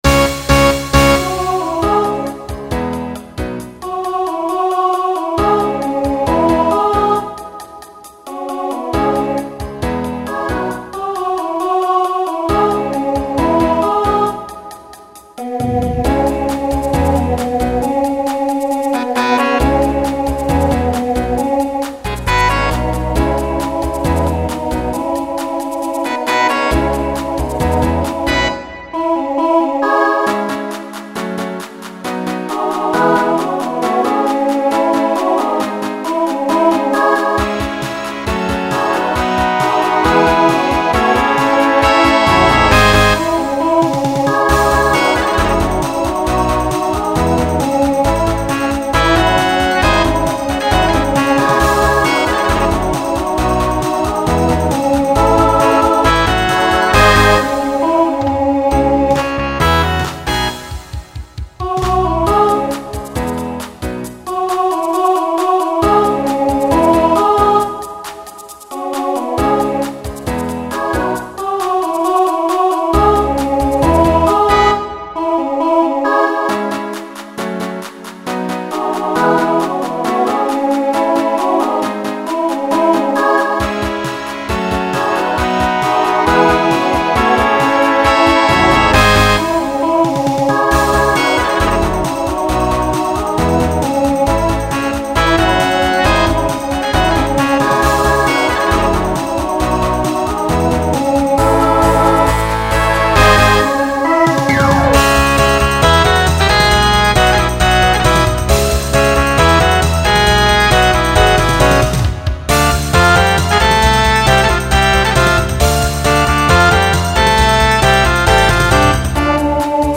Pop/Dance Instrumental combo
Transition Voicing SSA